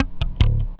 10BASS02  -R.wav